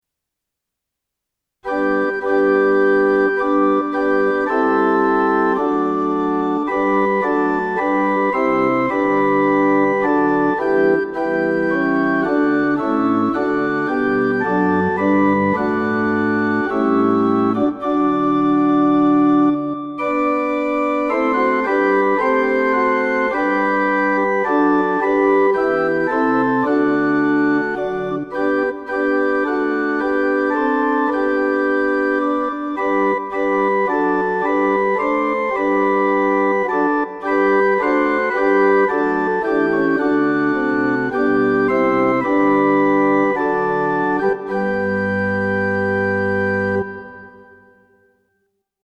O Come All Ye Faithful – Backing | Ipswich Hospital Community Choir
O-Come-All-Ye-Faithful-Backing.mp3